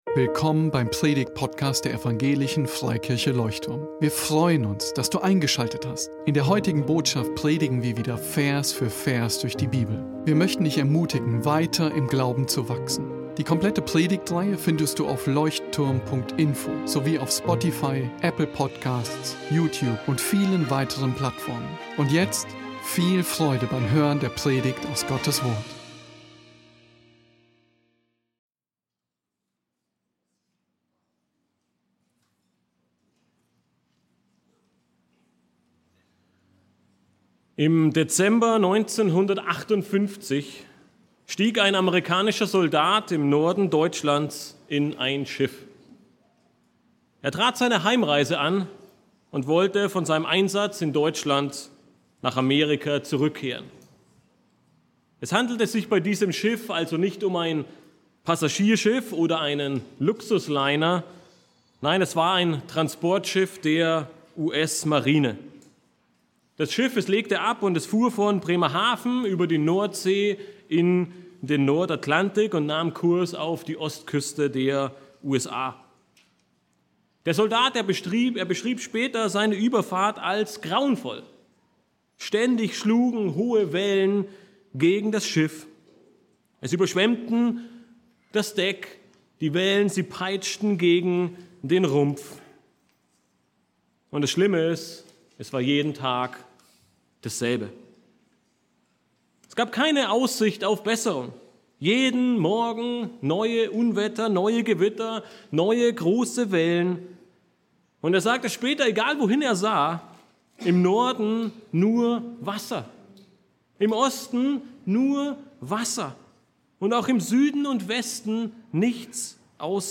Leuchtturm Predigtpodcast